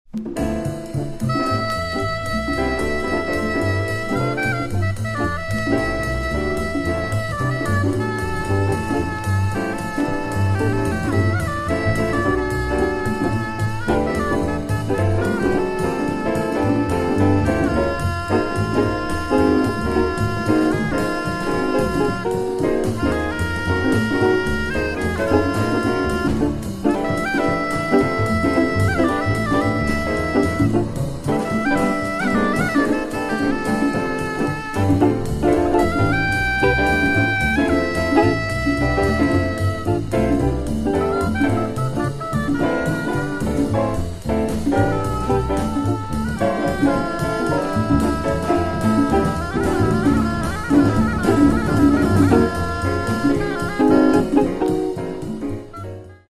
Beautiful Swedish jazz album recorded for dance lessons.